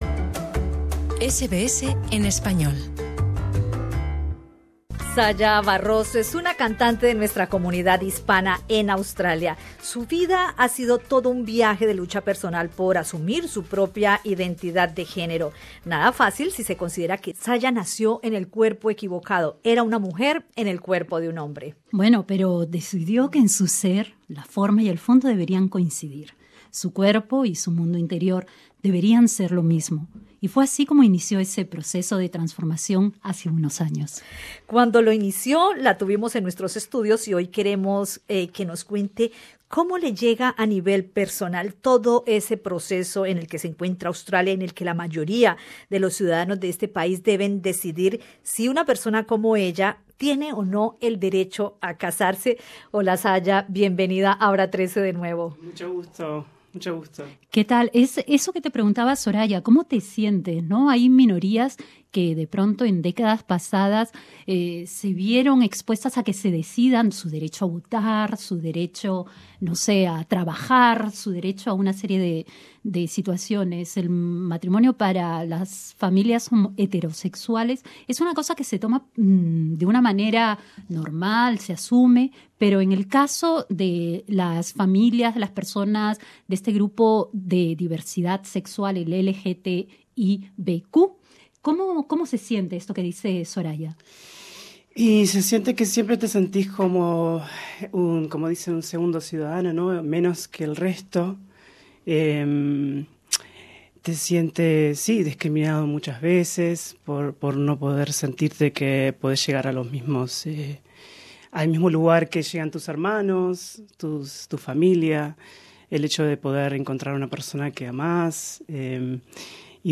En entrevista en Radio SBS, nos cuenta cómo siente a nivel personal ese proceso en el que los ciudadanos australianos deben decidir si una persona como ella, de la minoría LGTBIQ, debe tener o no el derecho a casarse.